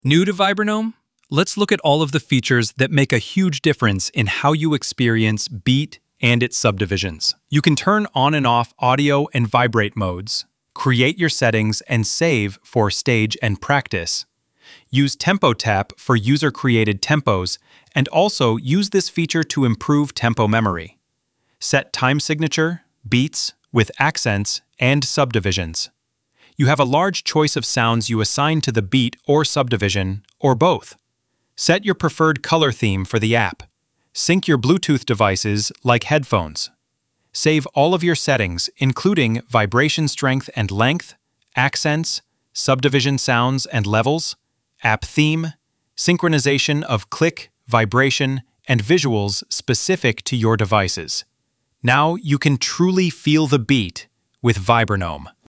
A short walkthrough of the app features.